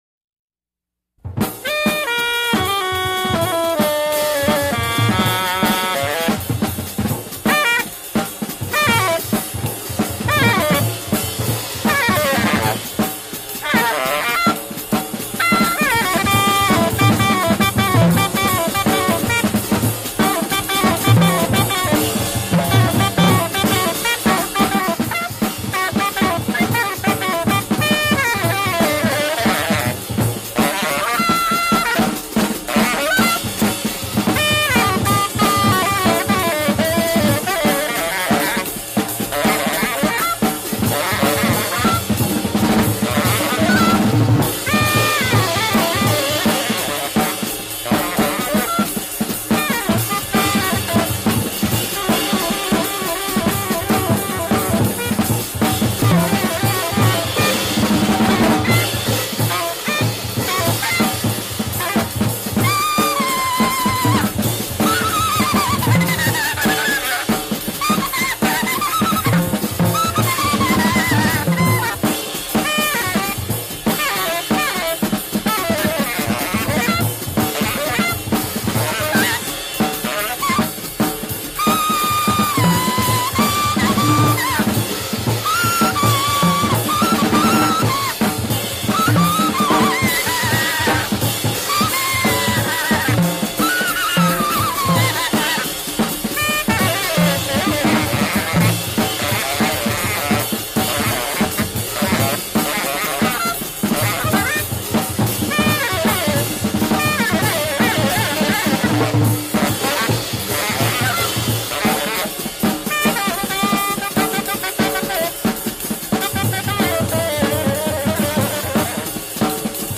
Incendiary and extremely rare free jazz ripper